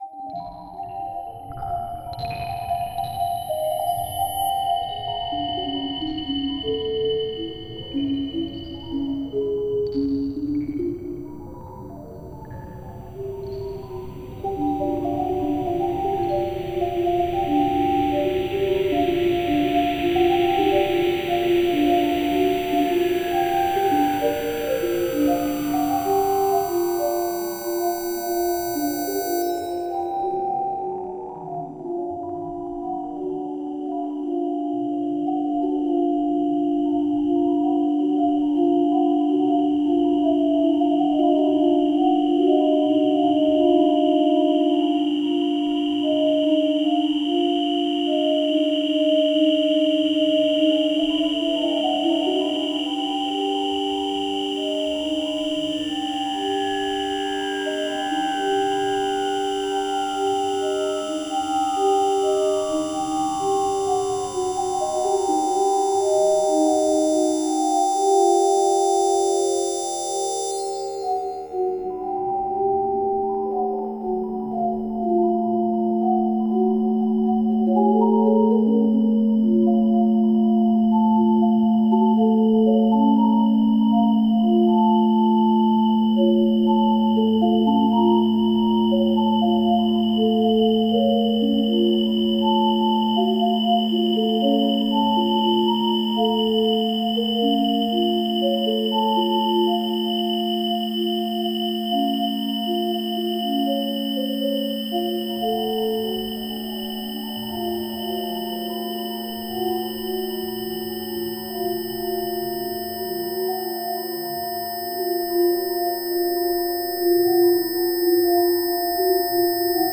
The song begins with an atonal fugue in two voices. The atonal fugue develops to another fugue, in 8 voices.